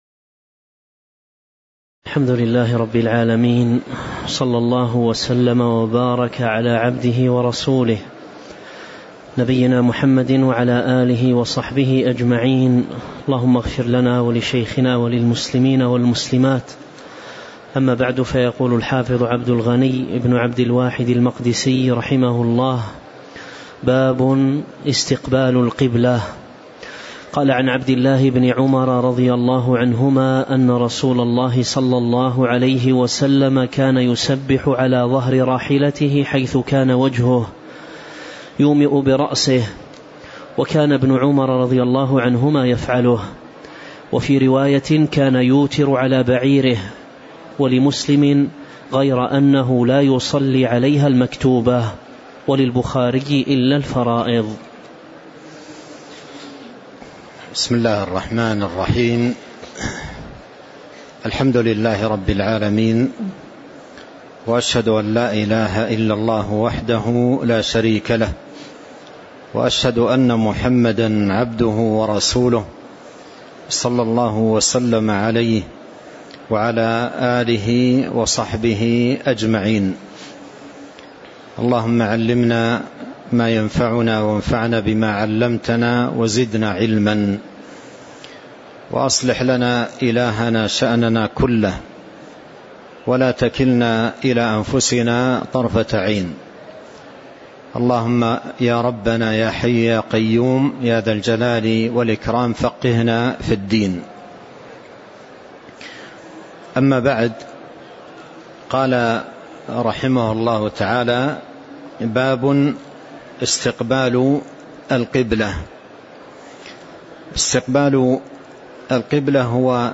تاريخ النشر ٢٨ ربيع الأول ١٤٤٤ هـ المكان: المسجد النبوي الشيخ: فضيلة الشيخ عبد الرزاق بن عبد المحسن البدر فضيلة الشيخ عبد الرزاق بن عبد المحسن البدر قوله: باب استقبال القبلة (05)